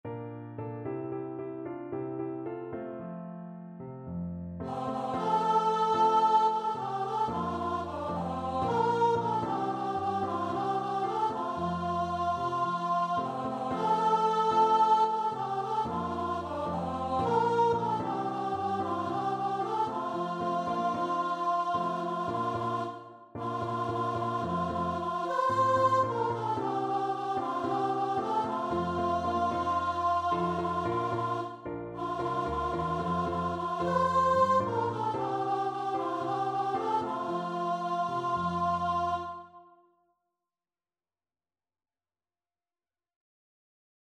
Free Sheet music for Choir (2 Voices)
4/4 (View more 4/4 Music)
F major (Sounding Pitch) (View more F major Music for Choir )
Joyfully = c.112
Traditional (View more Traditional Choir Music)
world (View more world Choir Music)